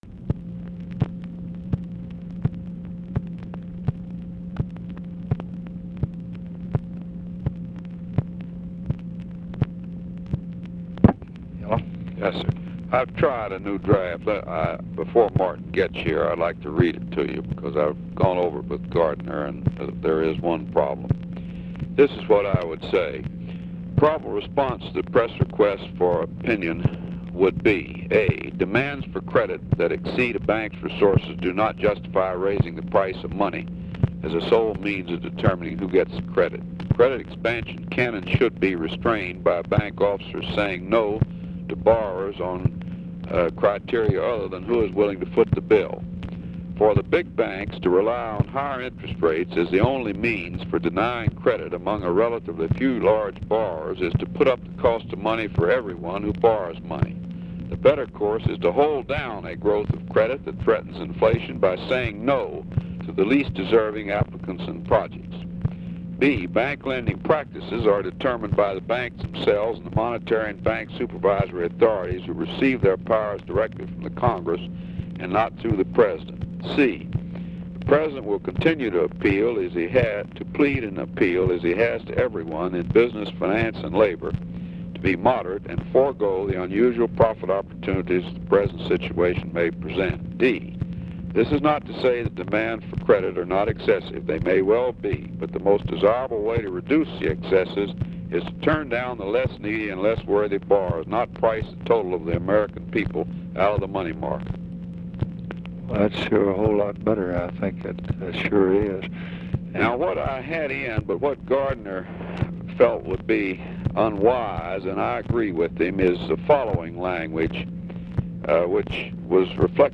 Conversation with HENRY FOWLER, March 7, 1966
Secret White House Tapes